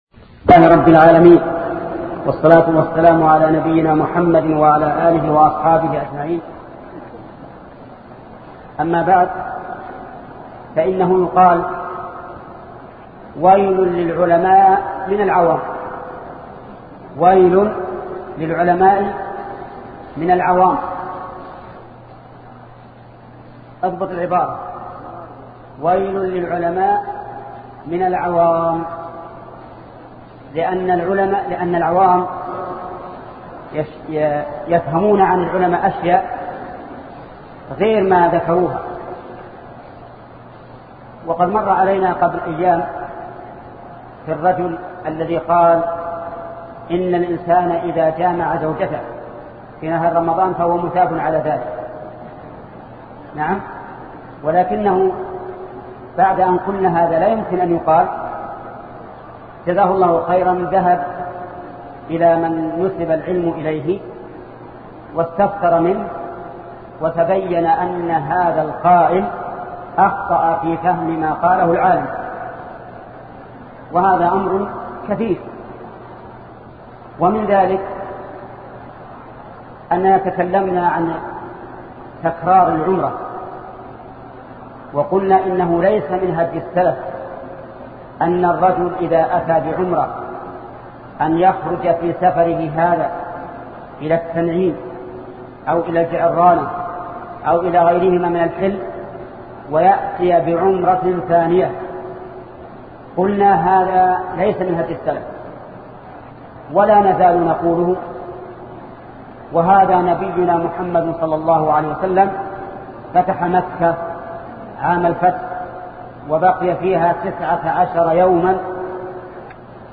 فتاوئ الحرم المكي 1407هـ 10